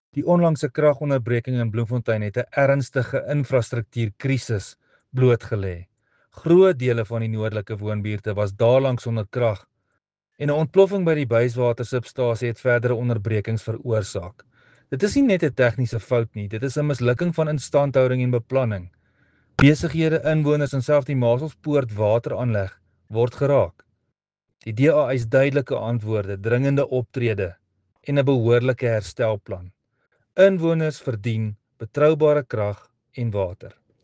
Afrikaans soundbites by Cllr Rudi Maartens and